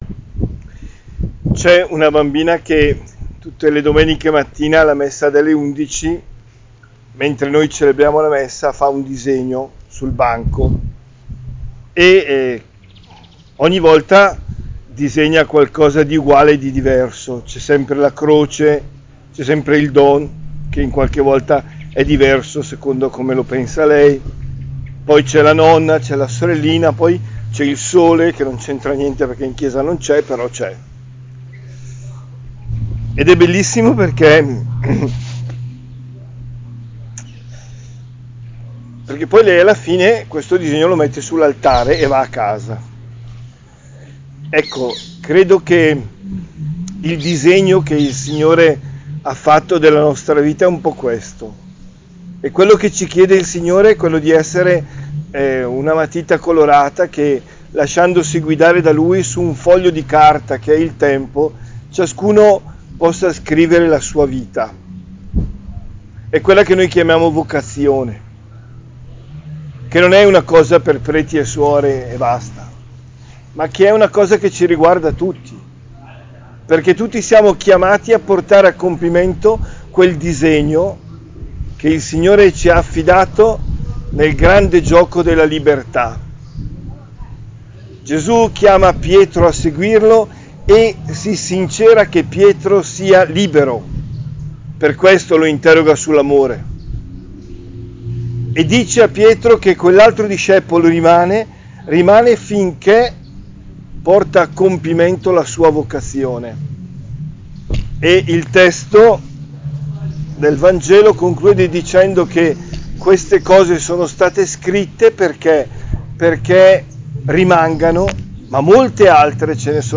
OMELIA DELLA CELEBRAZIONE AL PRIMATO